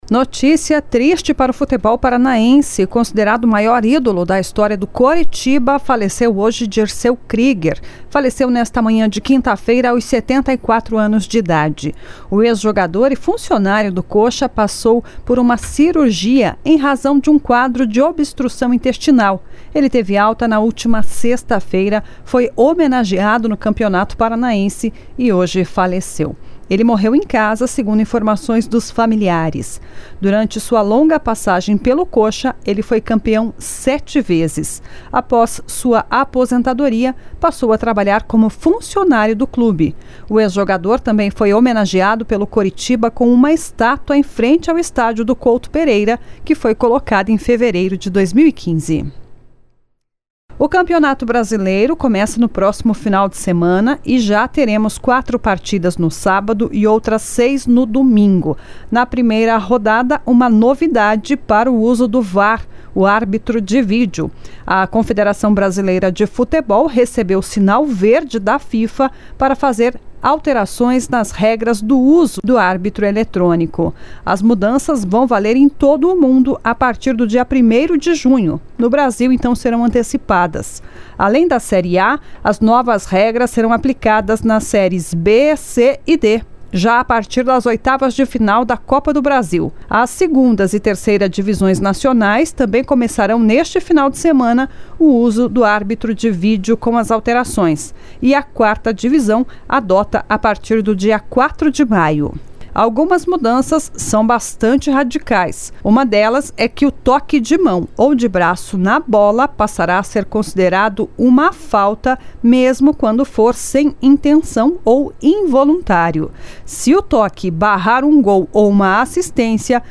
Giro Esportivo SEM TRILHA